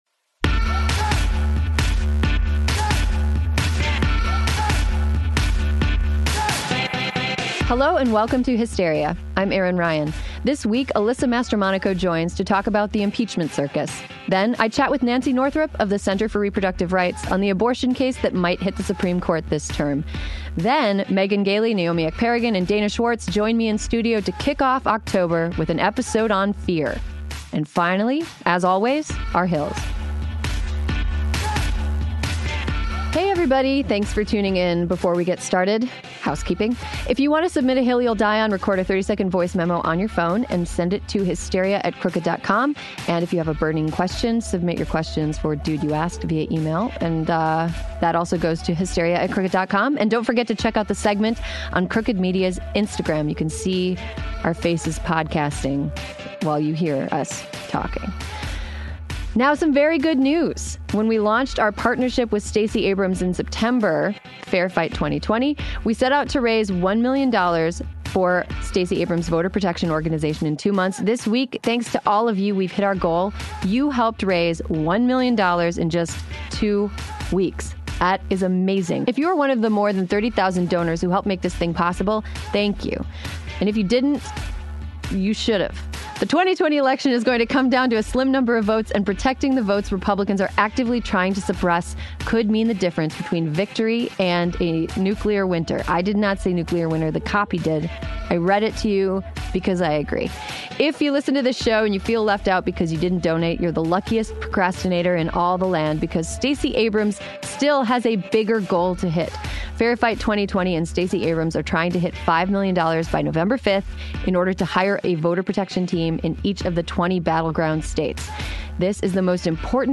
join me in studio to kick off October with an episode on fear.